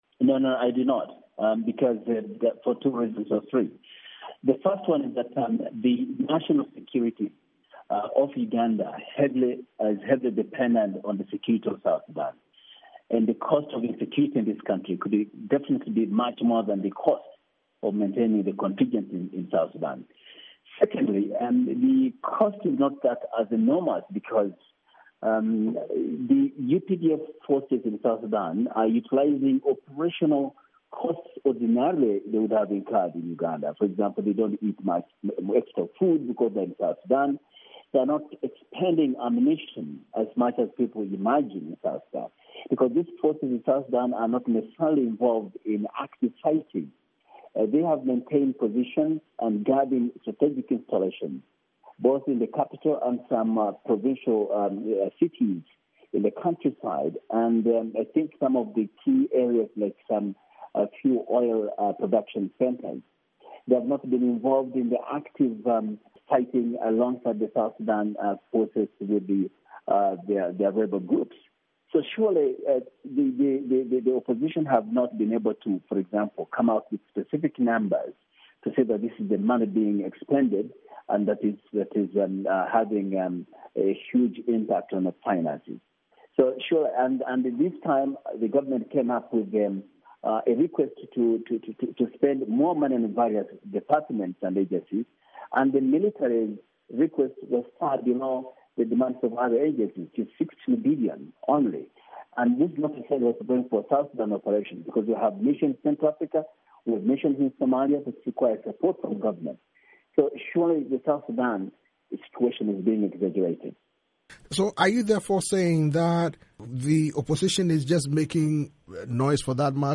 interview with Simon Mulongo, legislator from Uganda's ruling Uganda NRM party